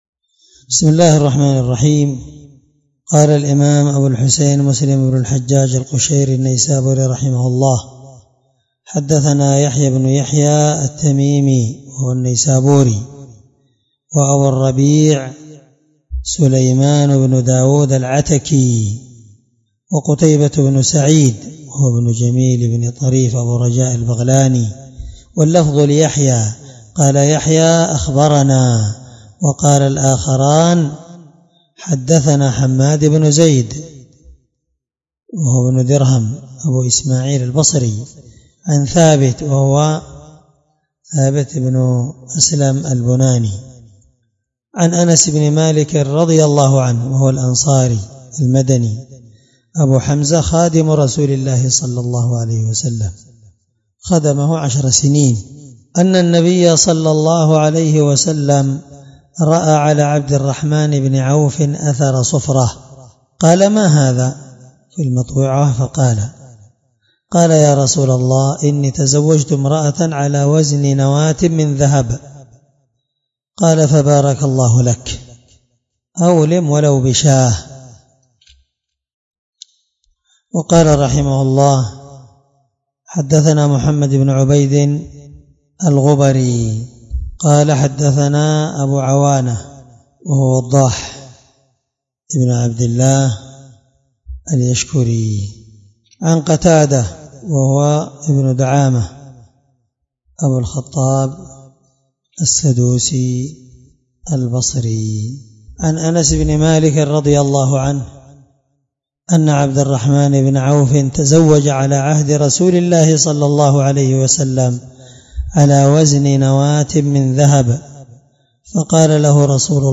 الدرس22من شرح كتاب النكاح حديث رقم(1427) من صحيح مسلم